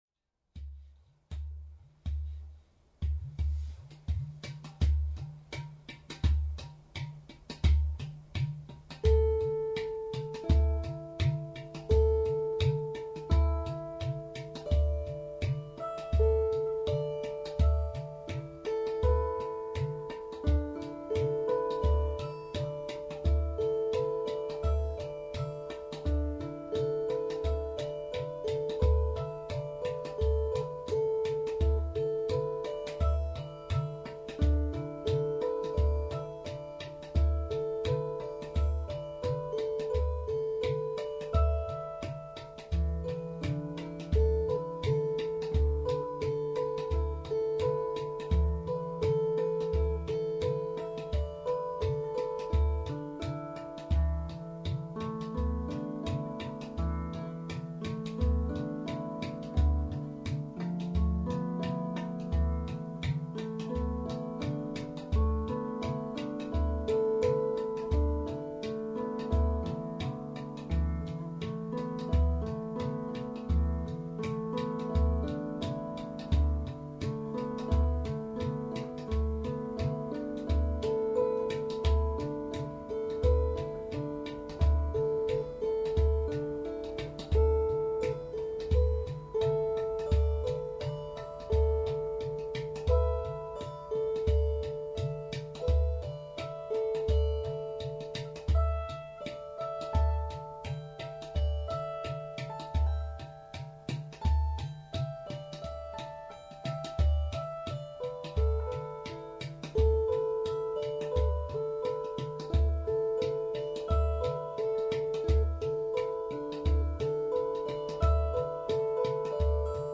五音階奏法でひたすらマイペースに弾きつづけるライアーと、どうせ試し録りだからとマイペースに叩くウドゥ。ゆるいというよりヌルいセッションがひとしきり続きます。…
» ライアーとウドゥドラムのセッション (3分32秒)
試しにウドゥの音を小さくすると、ライアーの音がどんどん小さくなります。
あとは予定調和に向かって音を小さくしていきました。
ライアー
ウドゥドラム
胴体を叩くとペシペシと高い音がする。胴の穴を叩くとボインボインという不思議な低音がする。